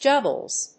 • / ˈdʒʌgʌlz(米国英語)
juggles.mp3